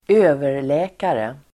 Uttal: [²'ö:ver_lä:kare]